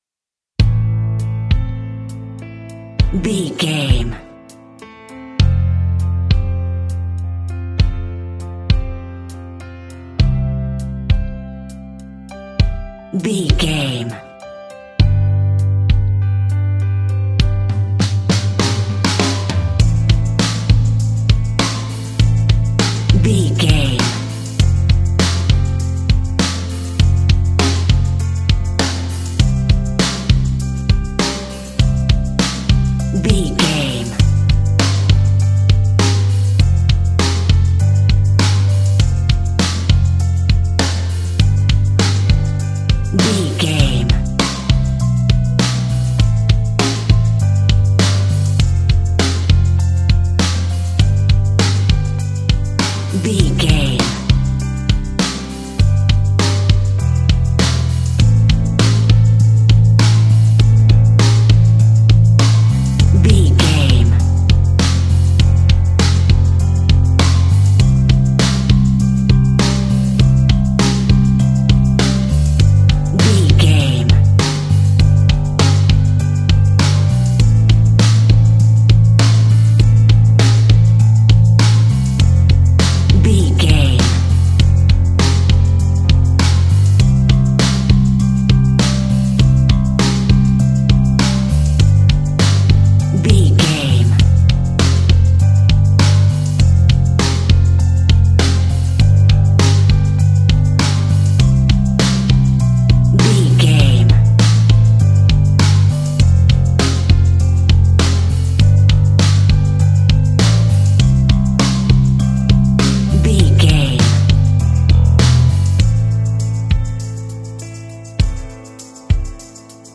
Ionian/Major
calm
melancholic
happy
energetic
smooth
soft
uplifting
electric guitar
bass guitar
drums
pop rock
indie pop
organ